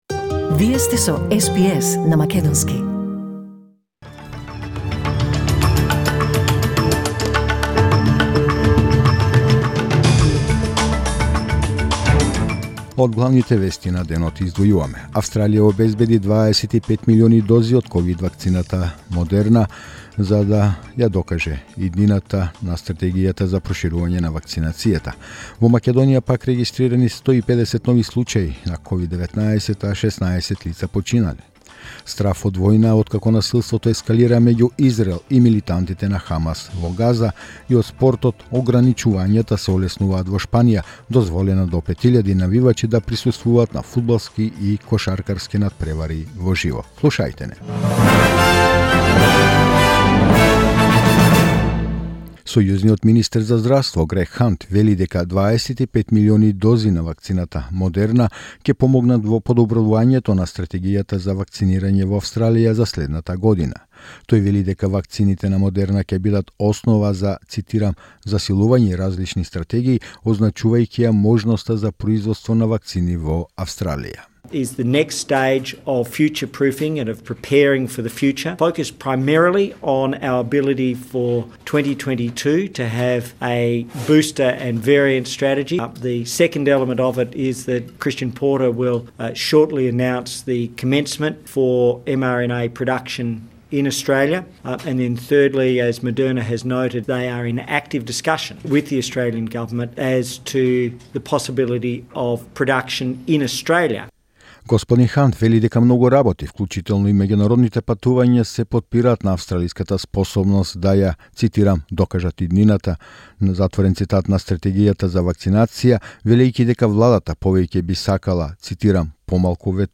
SBS News in Macedonian 13 May 2021